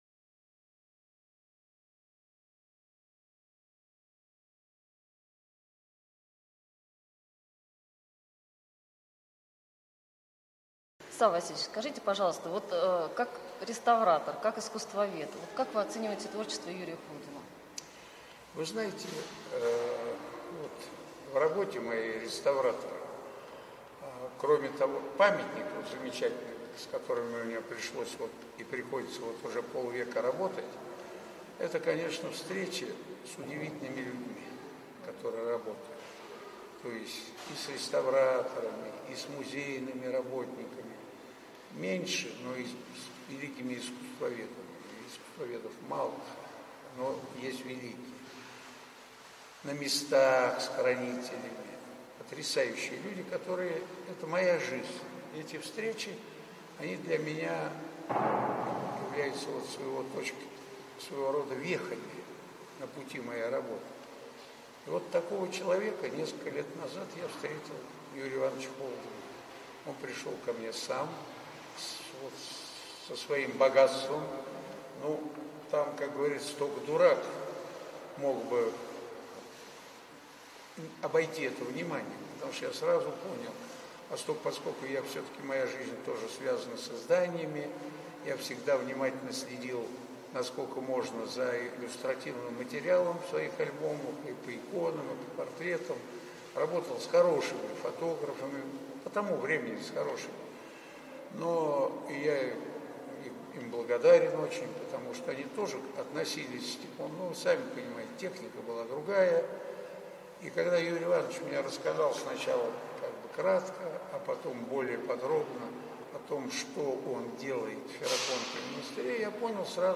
Фрагмент интервью С.В. Ямщикова о Юрии Холдине.
Подкасты Наши светочи Интервью Саввы Ямщикова на выставке "Памяти Юрия Холдина" Фрагмент интервью С.В. Ямщикова о Юрии Холдине.